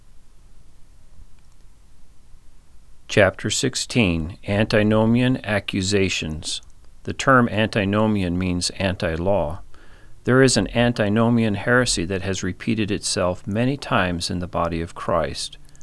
Under that towel is a stone pestle, we brought up from Mexico. It cradles the mike.
I went back to page five of this thread and, with the updated Audacity program, was able to unpin the meter and adjust the microphone settings higher.
It does sound much better, but I know there were earlier tests that sounded even better yet.
The voice is fine. It’s the Room Tone, the noise when you stop talking.
Again, your vocal tones are perfect. Nobody can tell you’re recording at home except for the noise level.